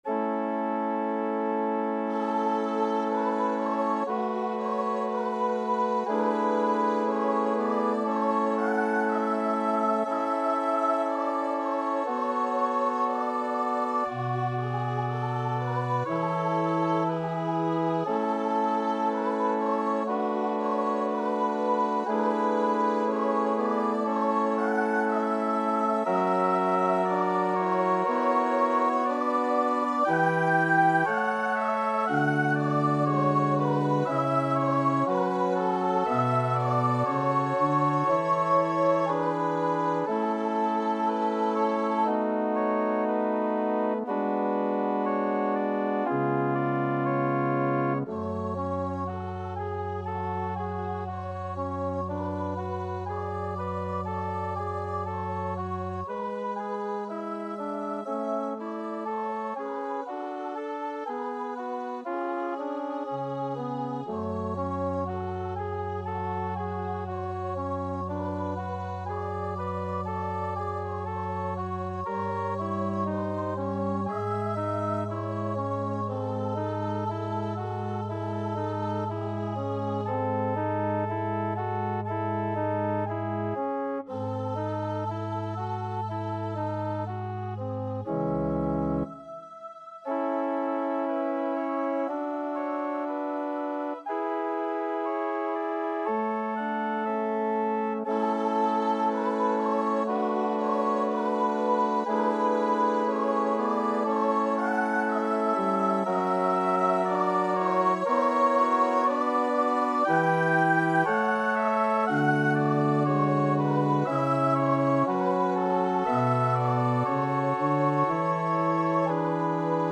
E5-G6
2/4 (View more 2/4 Music)
Classical (View more Classical Voice Music)